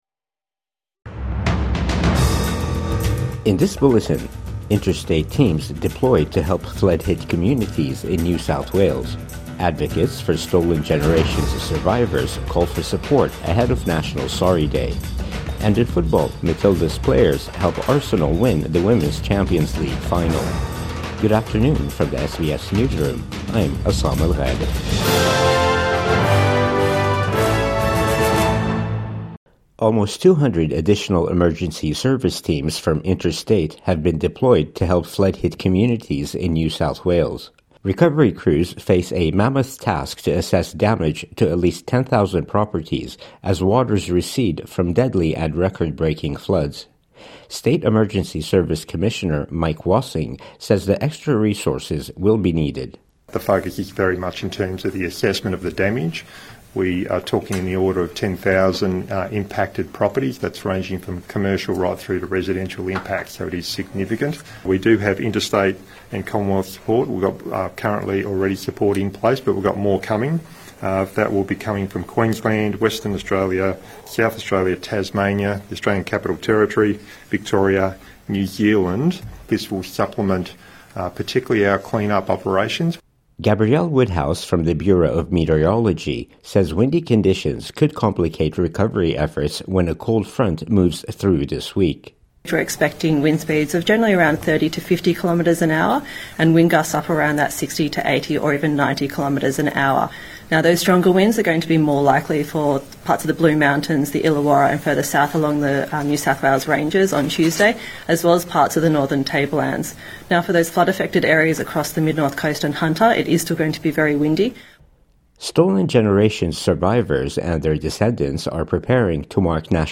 Midday News Bulletin 25 May 2025